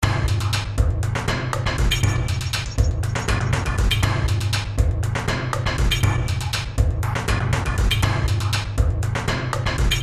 描述：肚皮跳动低
标签： 120 bpm Fusion Loops Percussion Loops 1.68 MB wav Key : Unknown
声道立体声